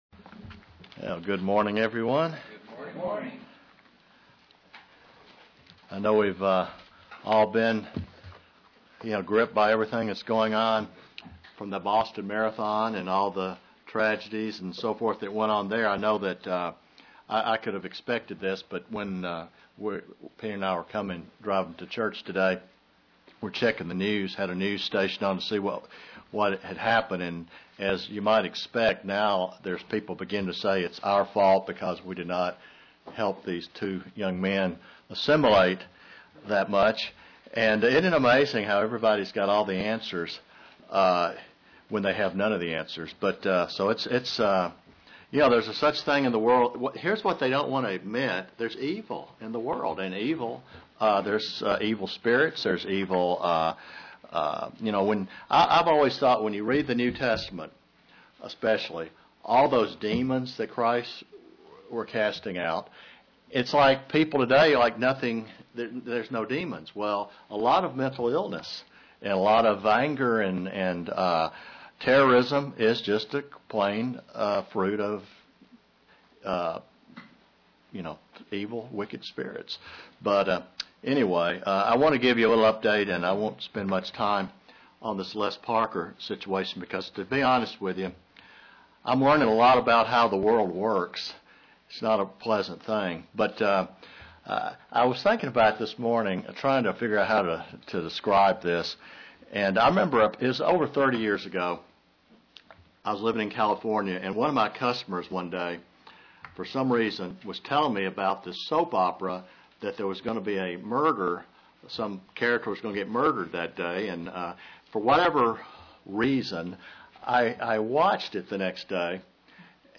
What to do when we are the offended? Continuation of a sermon series on reconciliation.